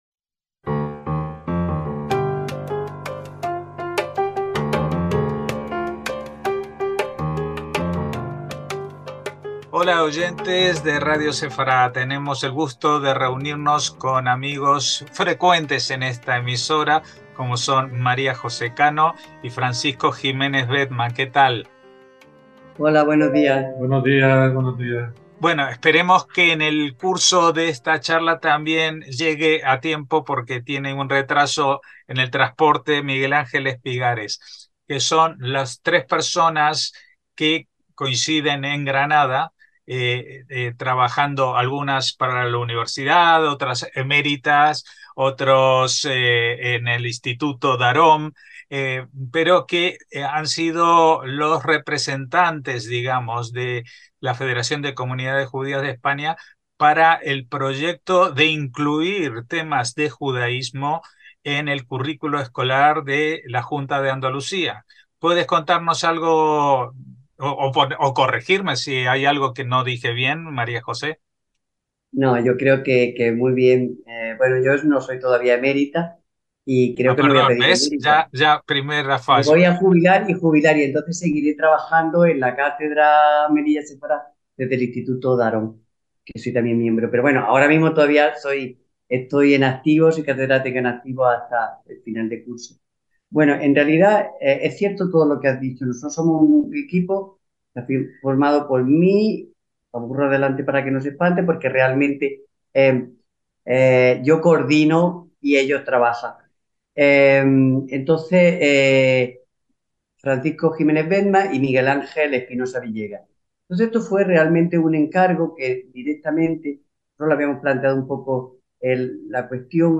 Hablamos de ello con tres personas que han estado (y siguen) vinculadas al proyecto que se pondrá en marcha a partir del próximo curos escolar